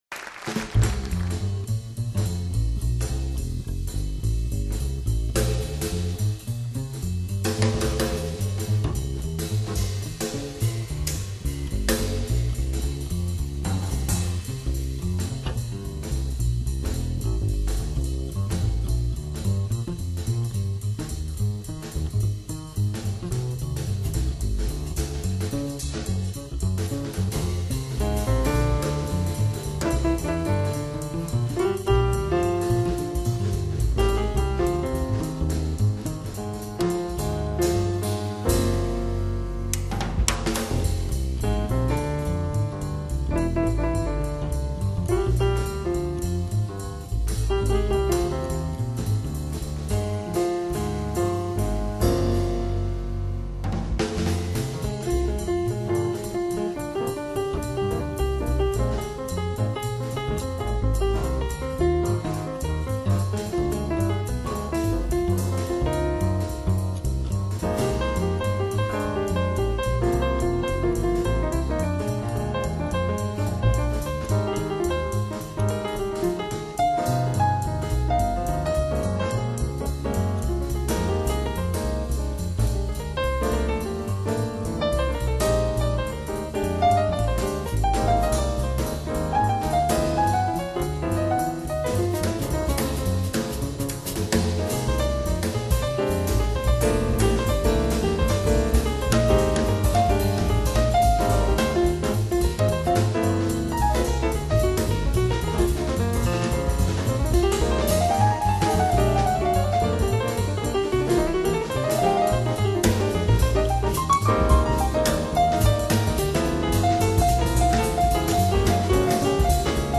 爵士专辑
這是網路上寫的,以一貫優雅抒情的旋律靈感傾吐對美麗而豐饒的地中海的深深依戀，